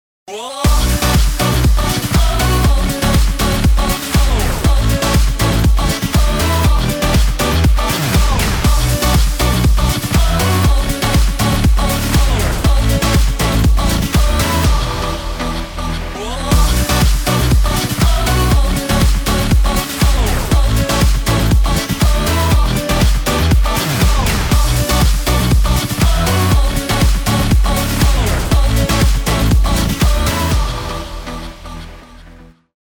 • Качество: 320, Stereo
поп
dance
электронные
Закольцованный проигрыш песни